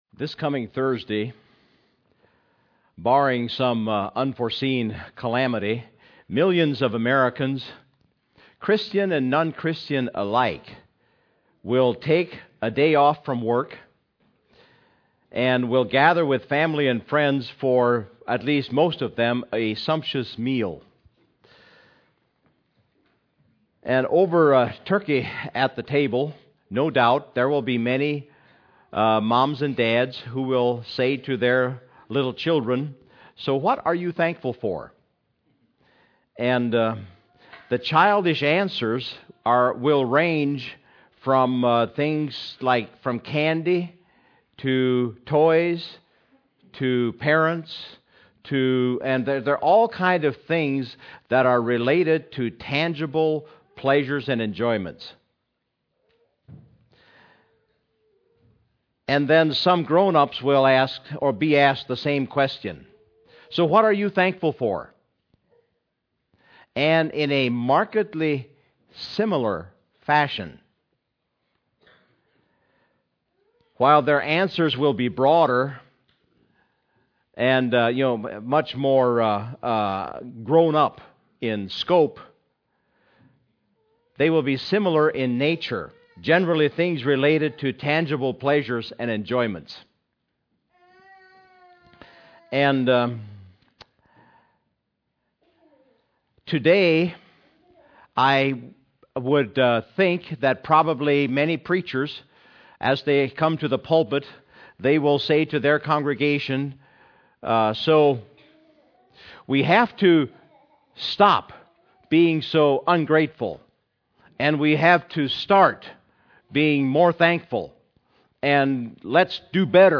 November 23, 2014 – Crosspointe Mennonite Church